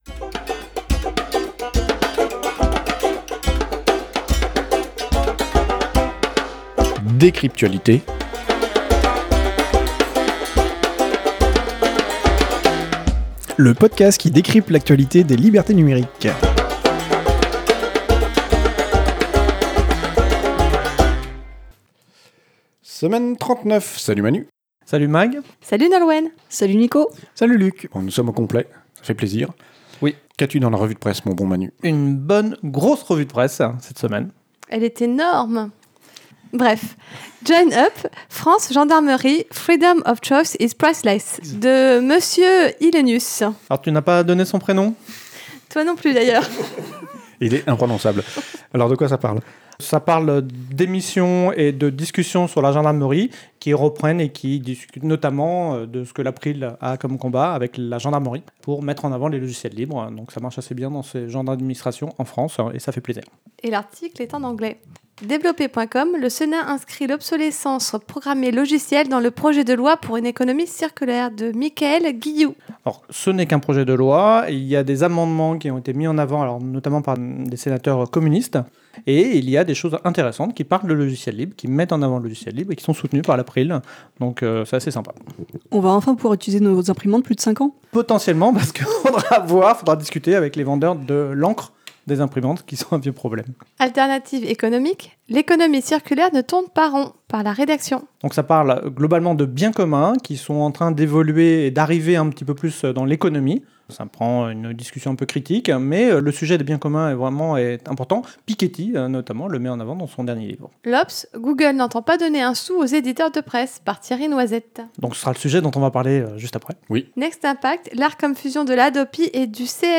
Studio d'enregistrement
Revue de presse pour la semaine 39 de l'année 2019